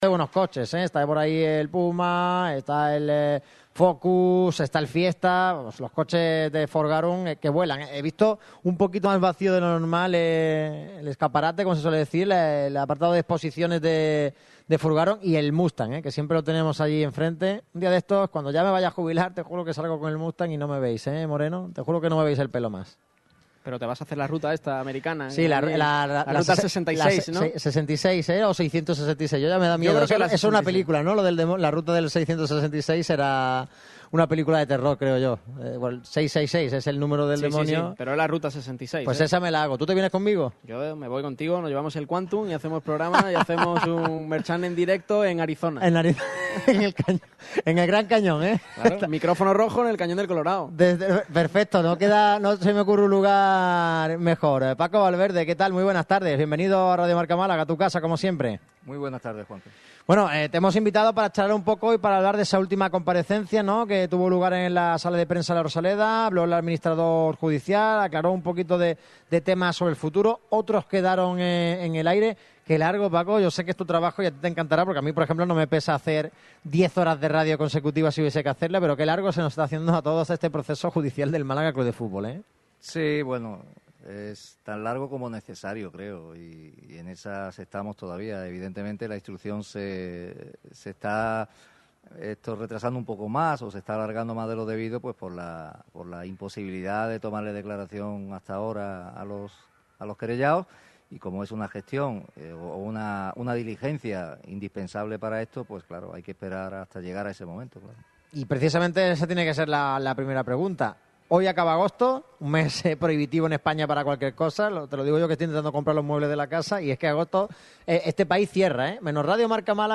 habló ante los micrófonos de Radio Marca Málaga.